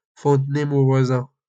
Fontenay-Mauvoisin (French pronunciation: [fɔ̃tnɛ movwazɛ̃]